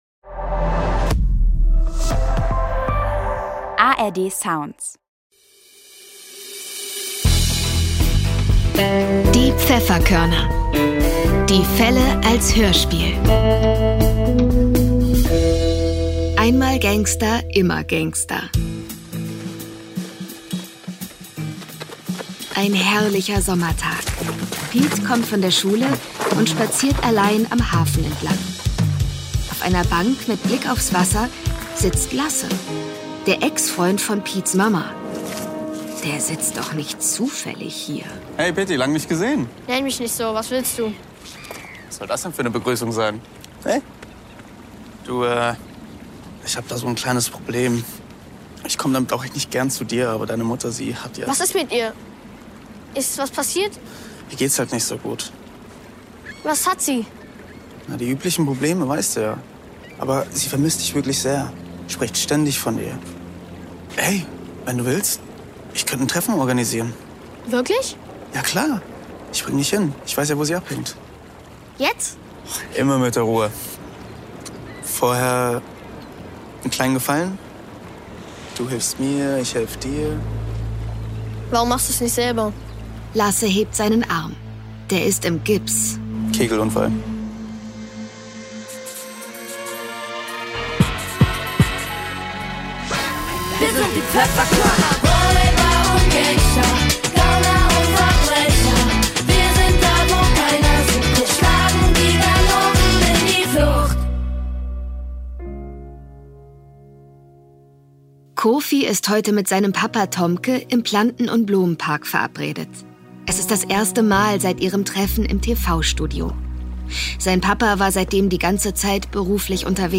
Einmal Gangster, immer Gangster (20/21) ~ Die Pfefferkörner - Die Fälle als Hörspiel Podcast